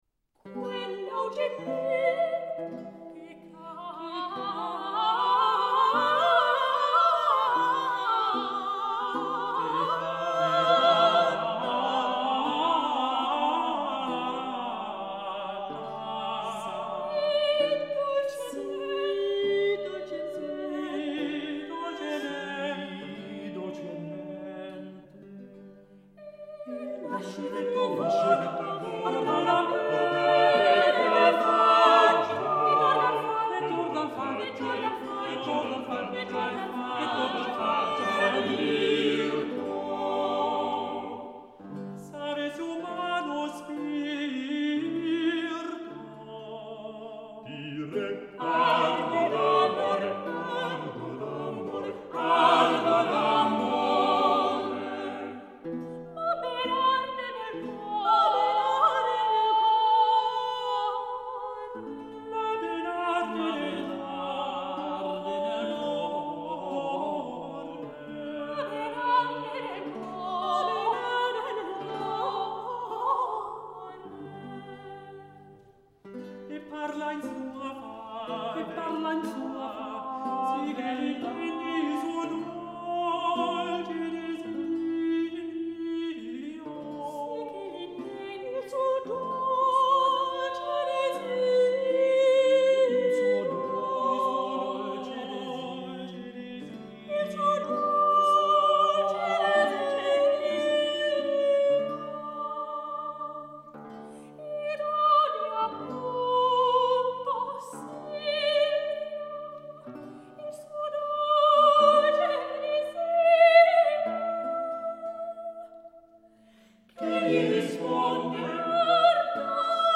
fünfstimmigen Madrigale
Sopran
Tenor
Leitung und Laute